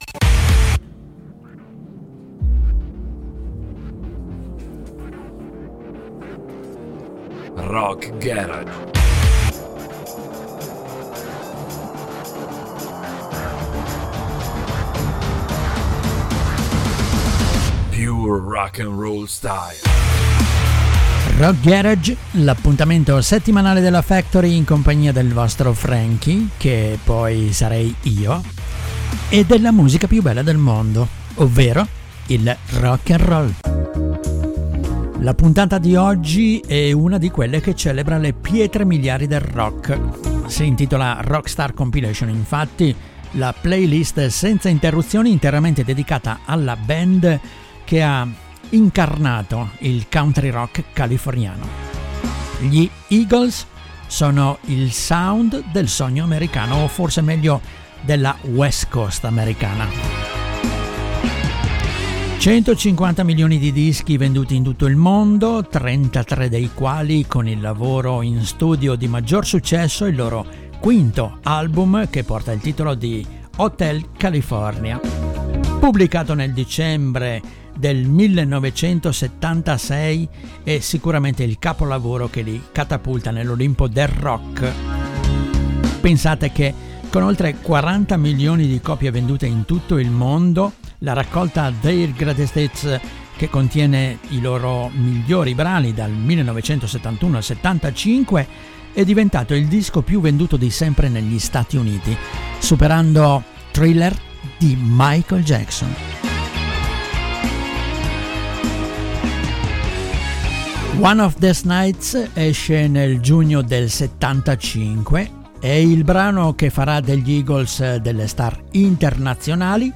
chitarra
batteria
banjo e mandolino
basso
il country-rock-californiano, il sound della West Coast